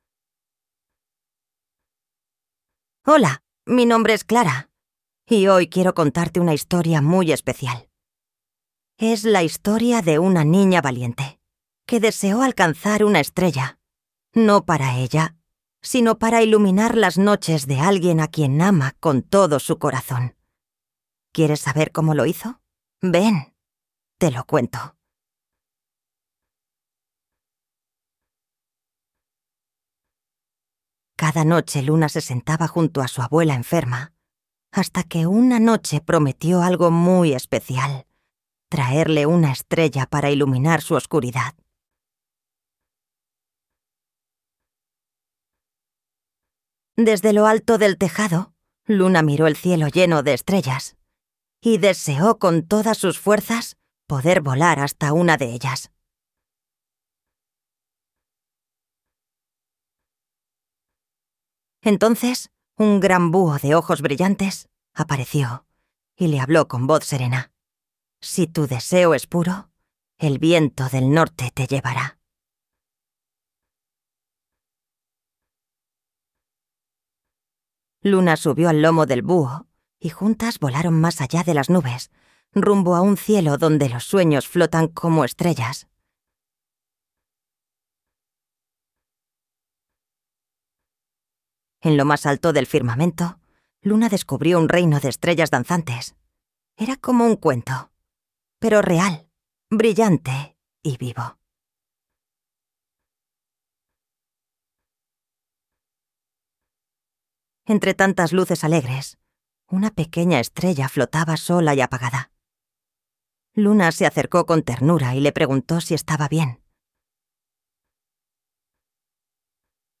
Algunos ejemplos de creación de voz con IA
Narración de cuentos infantiles o relatos
Las voces con IA permiten narrar cuentos con tono expresivo y adaptado a la edad del público.
Cuento-La-nina-que-volo-a-por-una-estrella.mp3